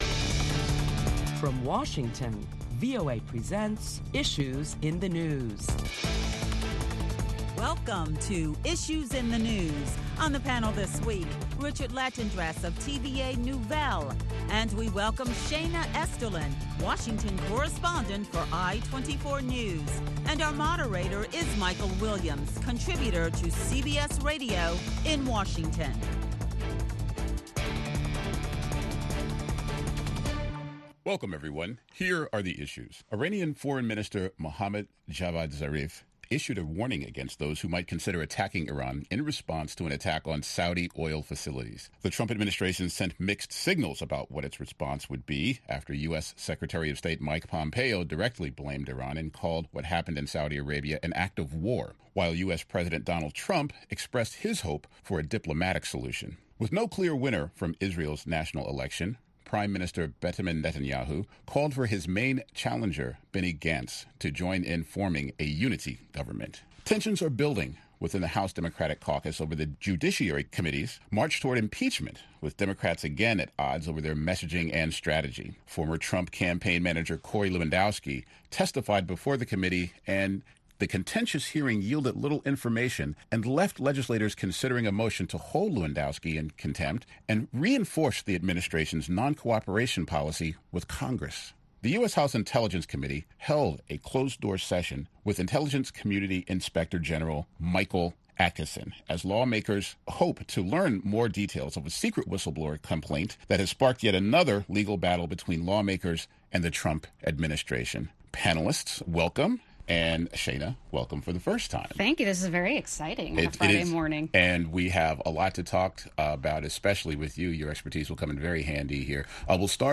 Listen to a panel of prominent Washington journalists as they deliberate the latest top stories that include tension builds within the House Democratic Caucus over a march towards impeaching President Trump. And, will a unity government be formed in Israel.